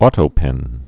au·to·pen
tō-pĕn)